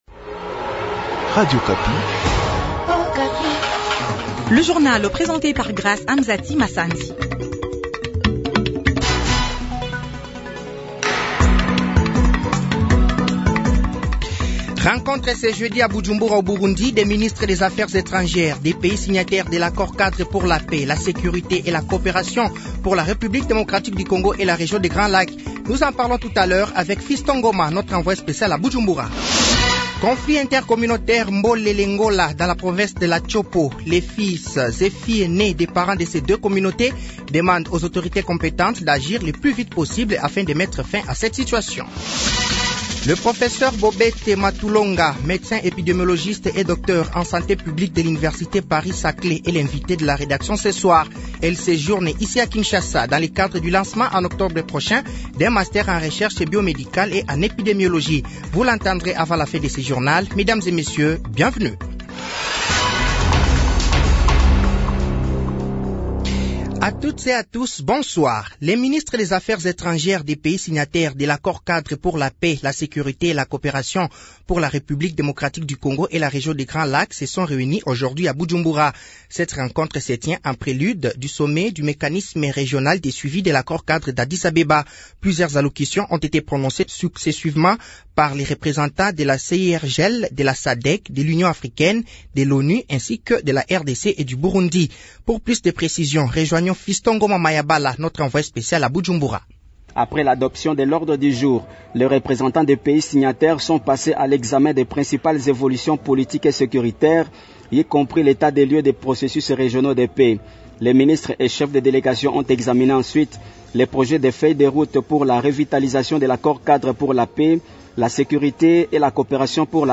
Journal de 18h de ce jeudi 04/05/2023